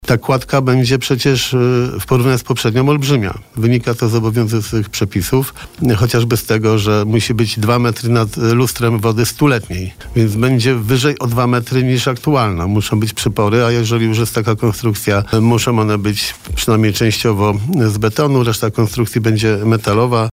– To trwa bardzo długo, bo nie da się jej zrobić tak jak kiedyś. Tak naprawę nie wiadomo kto i kiedy ją zrobił; drewnianą kładkę, bardzo fajną przez lata. Niestety się wyeksploatowała. W tej chwili rozpoczęliśmy całą procedurę budowlaną […]. W porównaniu z poprzednią ta kładka będzie olbrzymia – mówił na naszej antenie Jarosław Klimaszewski.